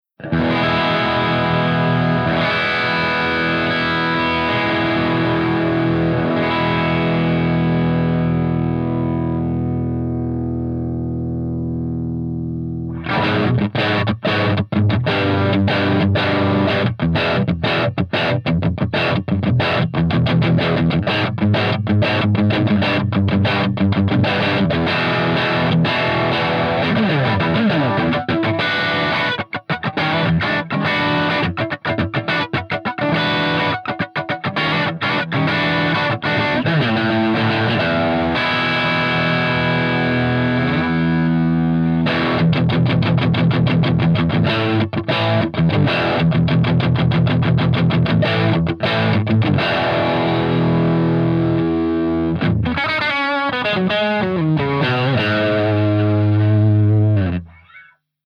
129_MARSHALLJCM800_CH2HIGHGAIN_V30_SC